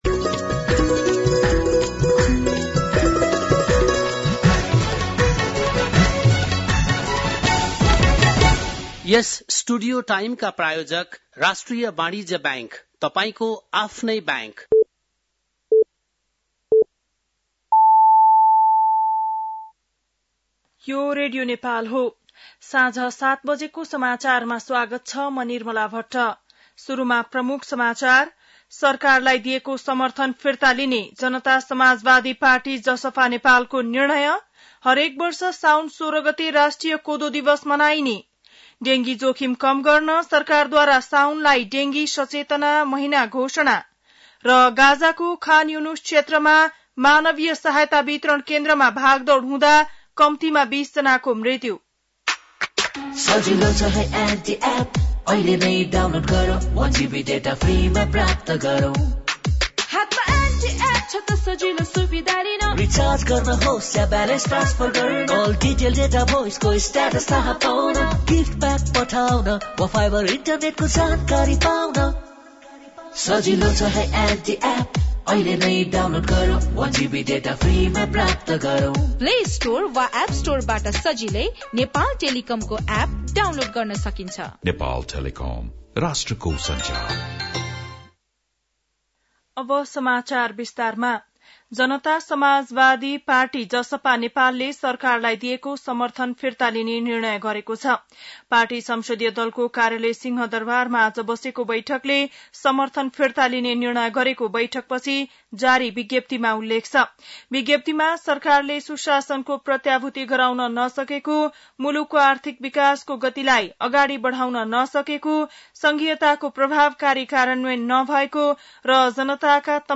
बेलुकी ७ बजेको नेपाली समाचार : ३२ असार , २०८२
7-PM-Nepali-NEWS-3-32.mp3